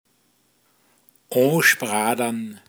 Pinzgauer Mundart Lexikon
Details zum Wort: o(n)spradan. Mundart Begriff für anspritzen